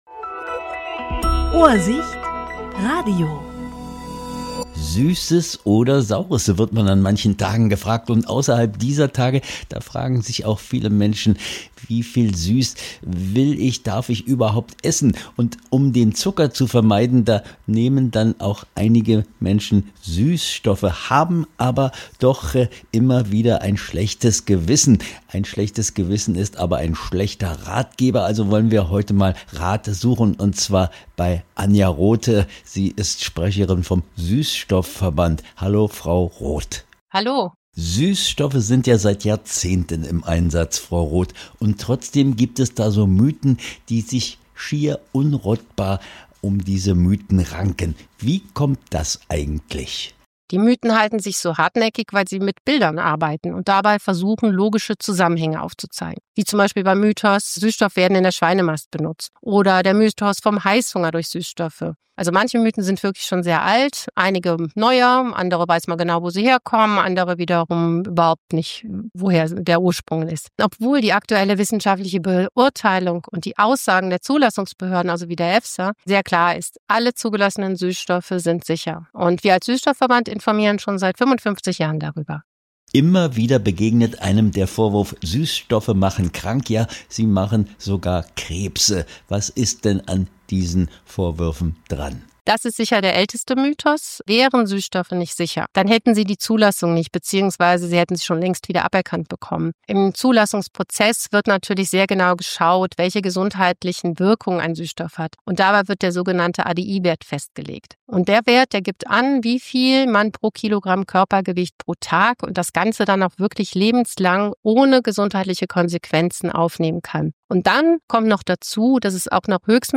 Interview 31.10.2025